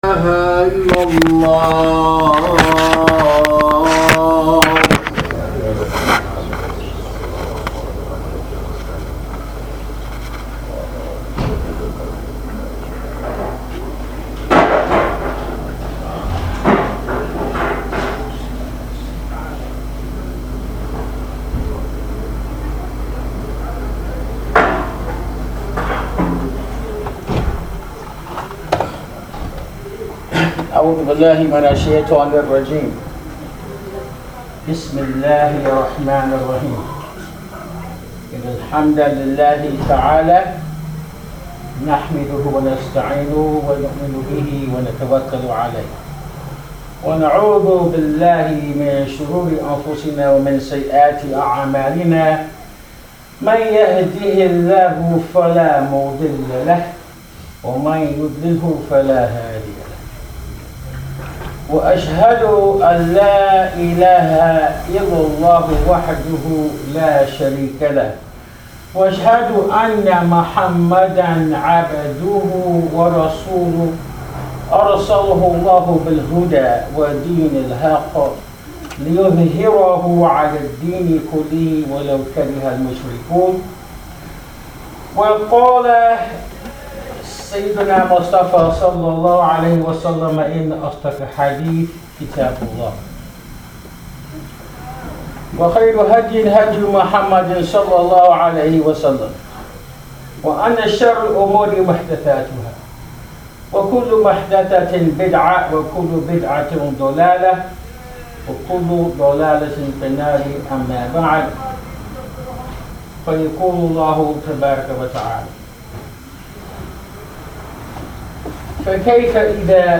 Audio Khutba: It is the Duty of the Ummah and Every Muslim to Be Bearers of Witness!